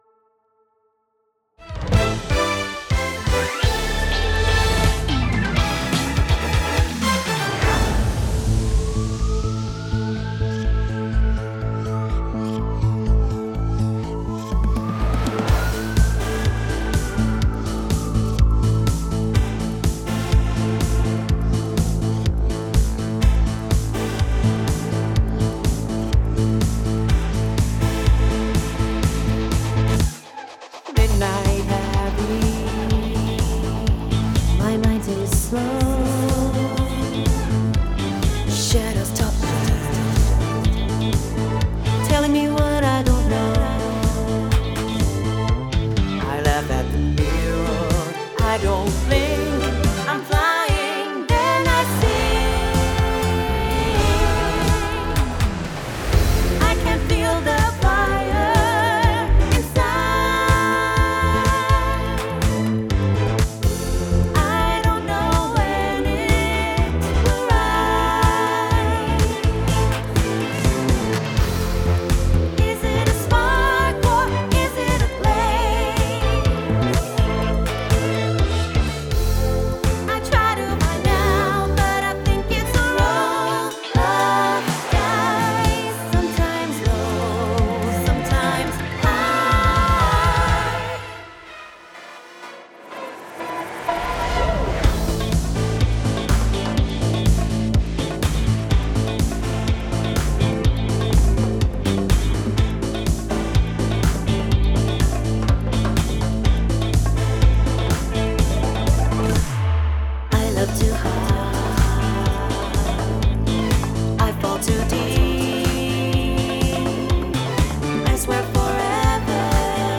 Disco Dance Track braucht mehr/neue Ohren
Die klingen eher orchestral statt satt nach Disco.
Dann übersteuert mein Mix, weil ich vergessen habe einen Track richtig zu routen.
Ja, ist wieder zu viel los, ich weiß.